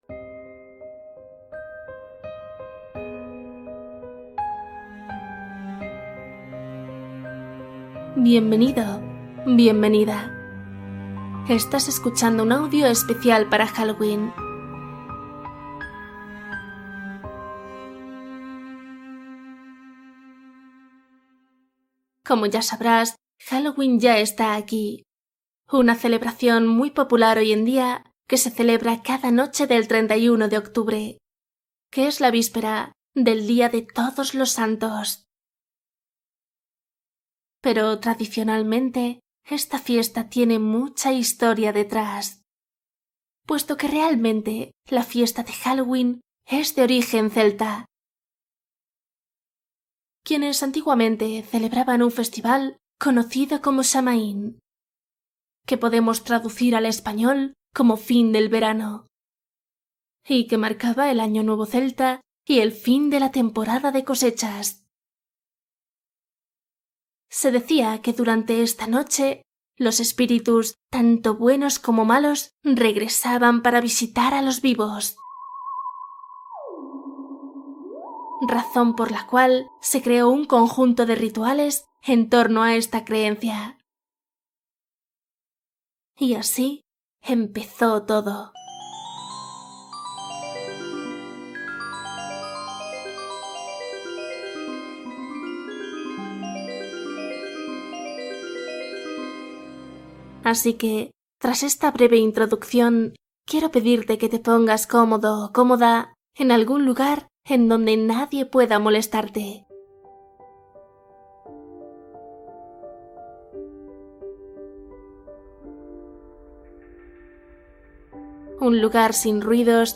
Meditación temática para trabajar el recuerdo y la despedida